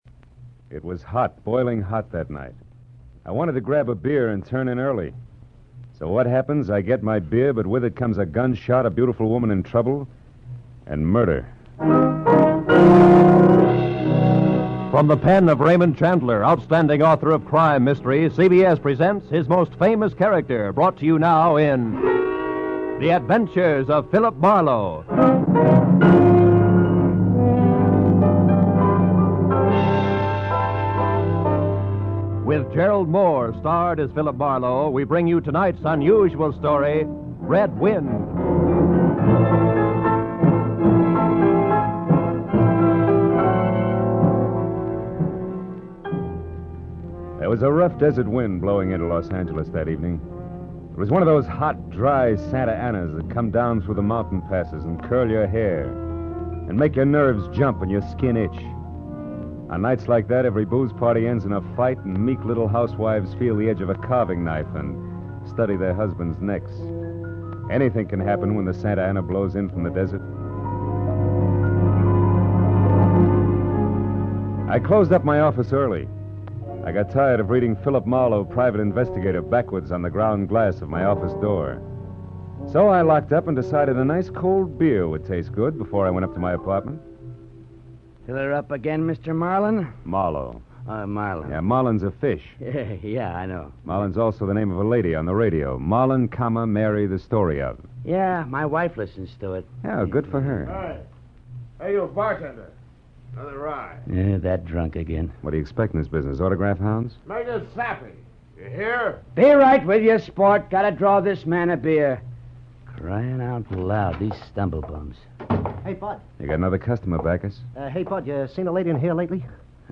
The program first aired 17 June 1947 on NBC radio under the title The New Adventures of Philip Marlowe, with Van Heflin playing Marlowe.